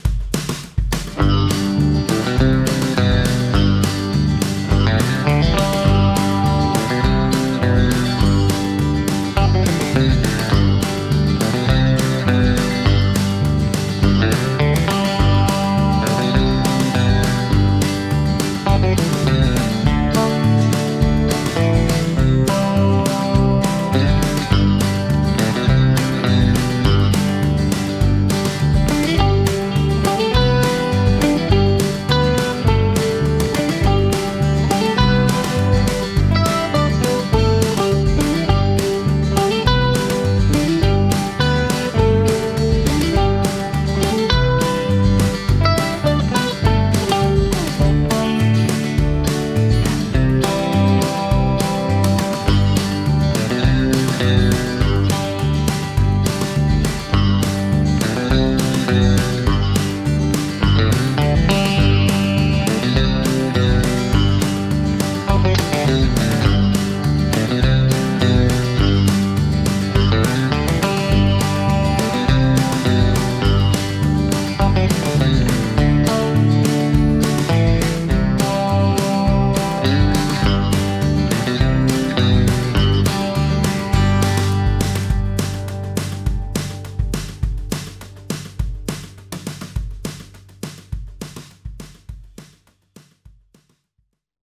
Country Stock Audio Tracks